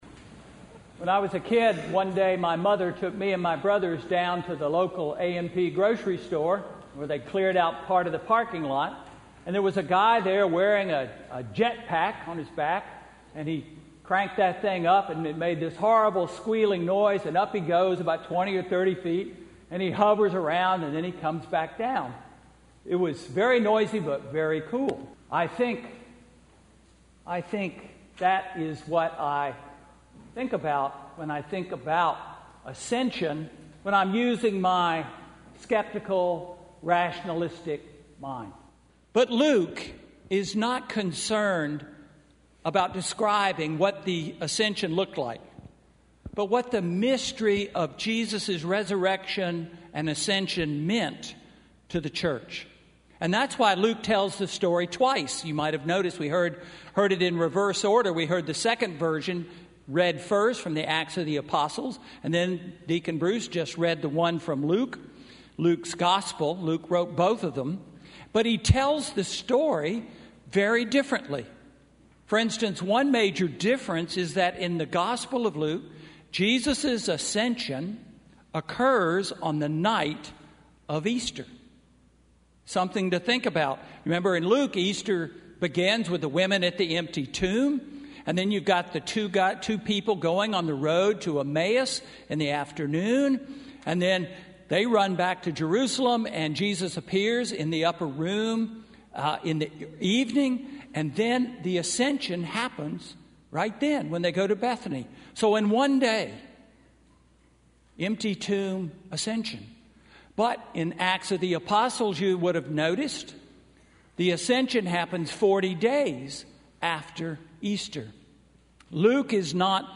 Sermon–May 13, 2018